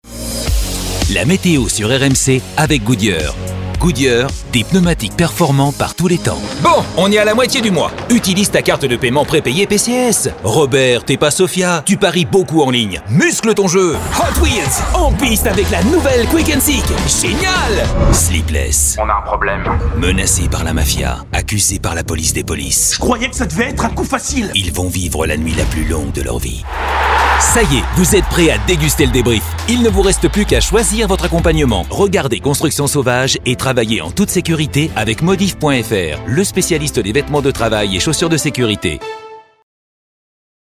Hello, I'm a french voice over with warm and deep voice for the narration.
Classical elocution for audiobook. fun and dynamic voice for commercials..
Sprechprobe: Werbung (Muttersprache):